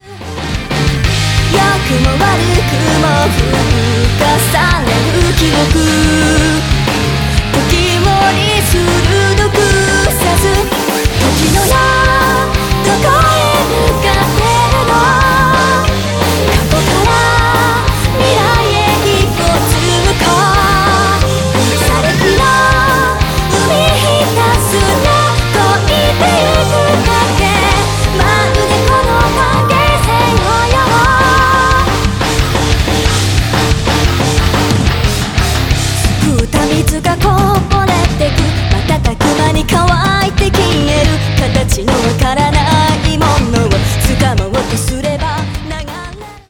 All Vocal,Lyrics,Chorus
Guitar
Bass